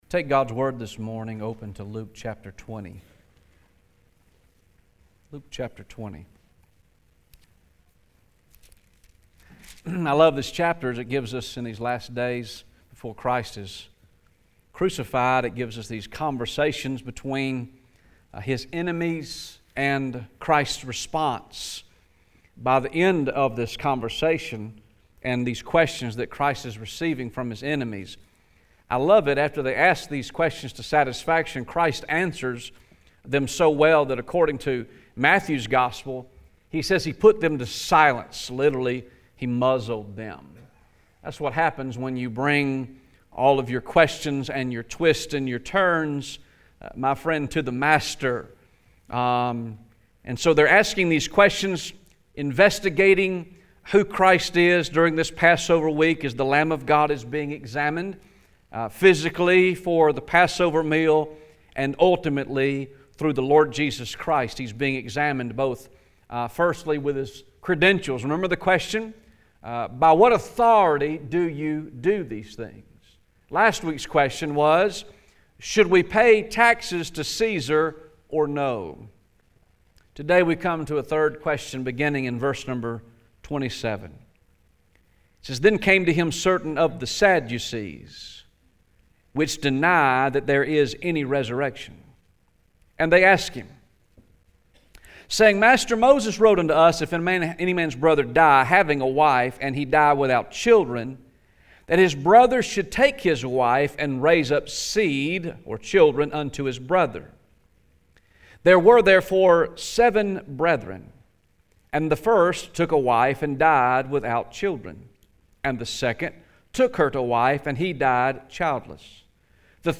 Sunday,October 20th, 2019 am service
Sermon